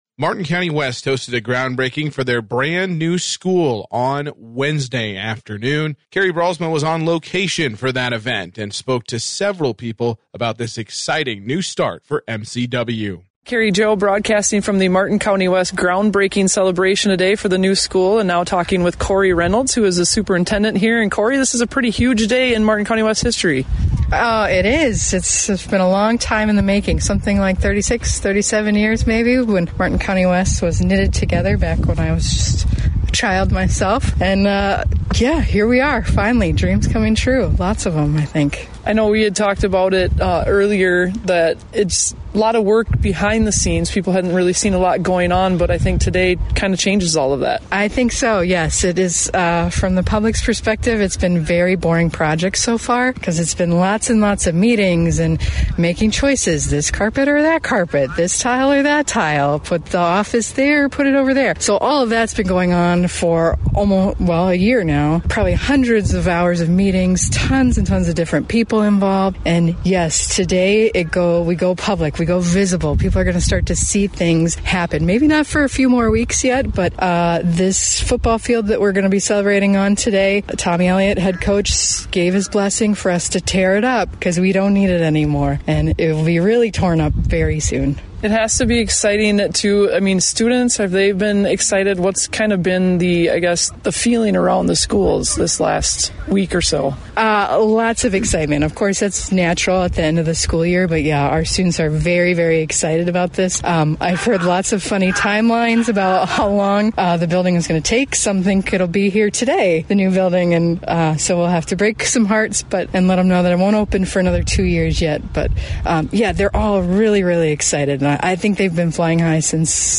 5-29-25-all-mcw-groundbreaking-interviews.mp3